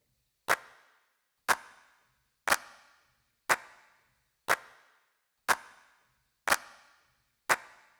17 Clap.wav